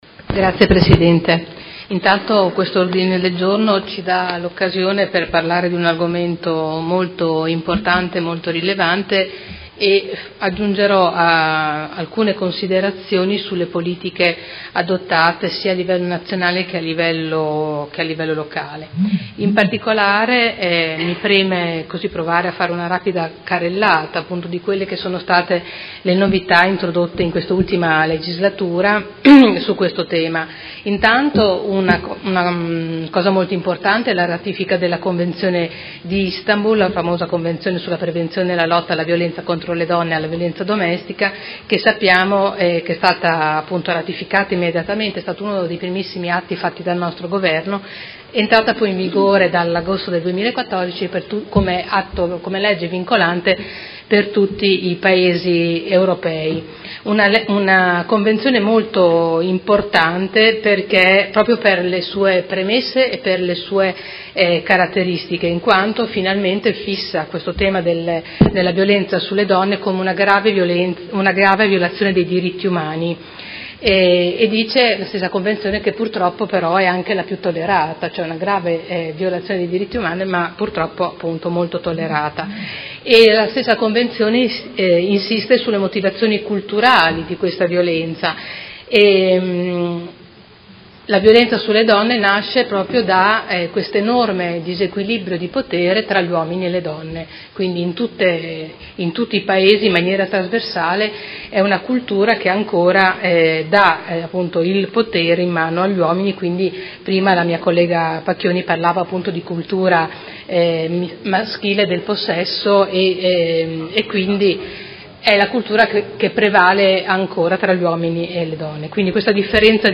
Seduta del 14/12/2017 Dibattito.